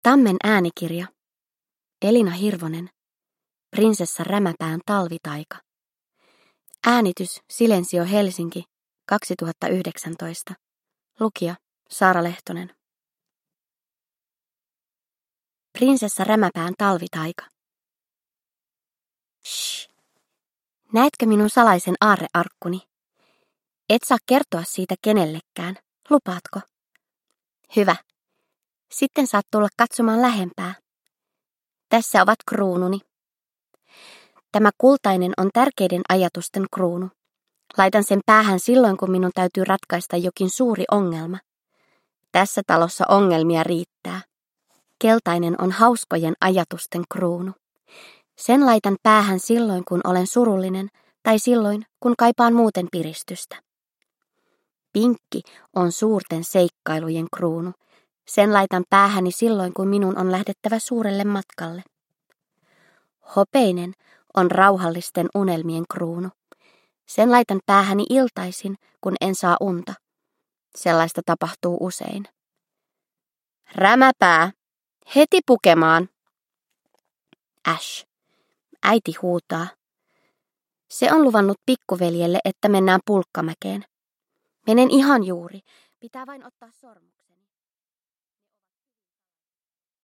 Prinsessa Rämäpään talvitaika – Ljudbok – Laddas ner